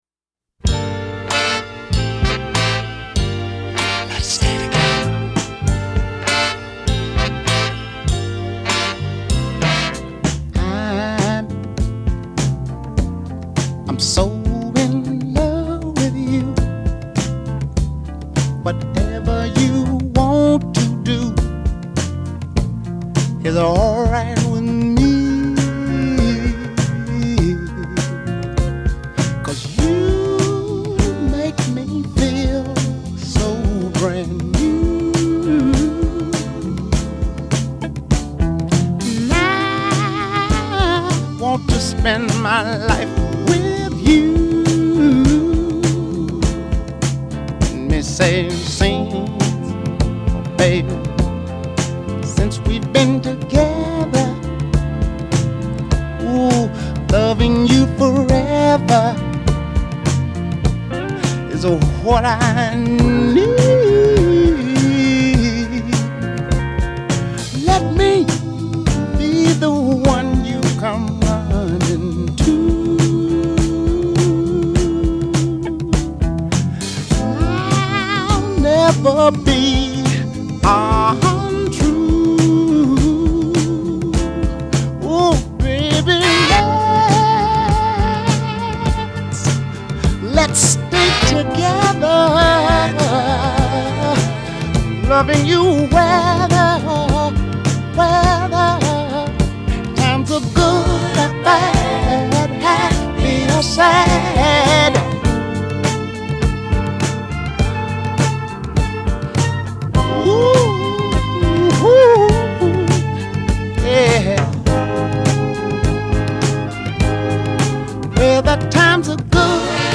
Posted by on December 2, 2014 in Soul/R&B and tagged , , .